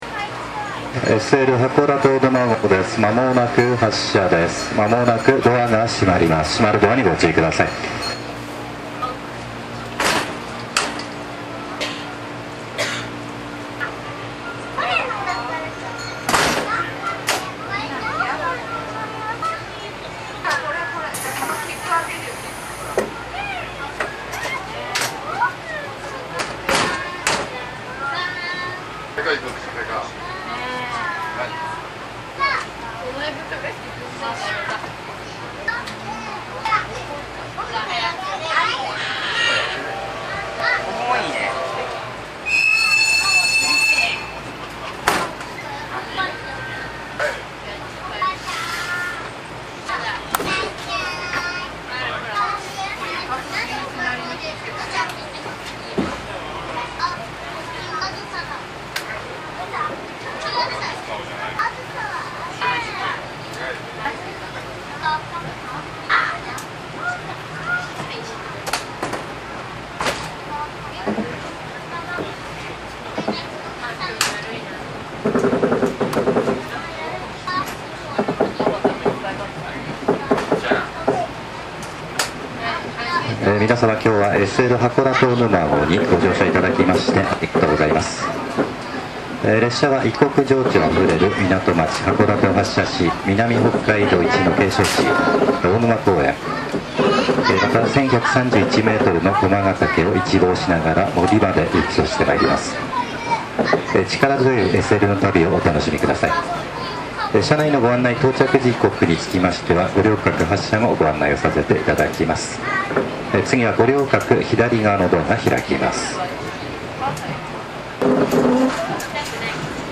発車アナウンス。00’40秒頃に応答汽笛。
中盤から客室乗務員のアナウンスになぜか拍手w。
020-hakodatehassha.mp3